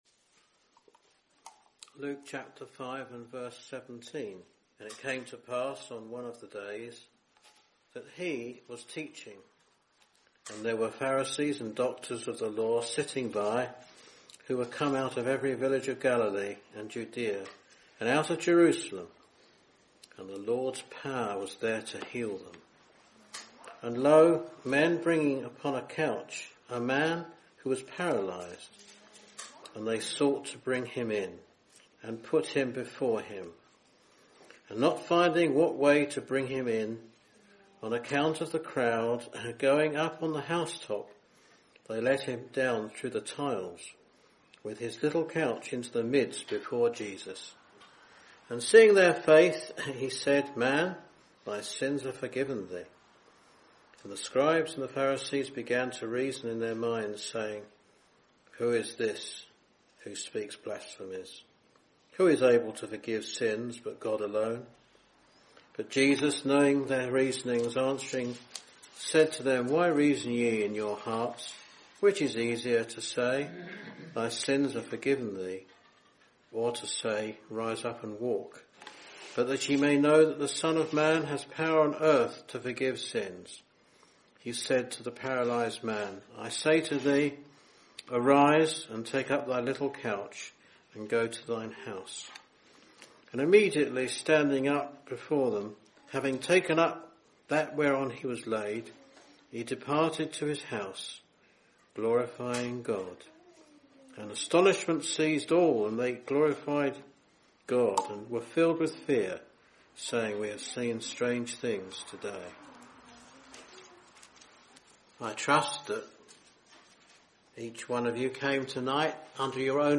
In this Gospel preaching, you will hear of the story when Jesus Heals a Paralytic.